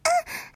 moan3.ogg